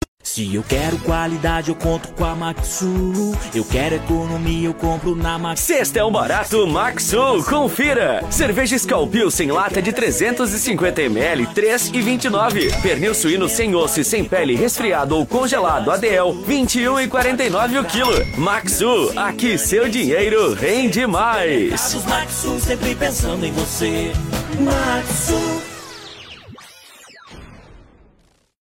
VAREJO: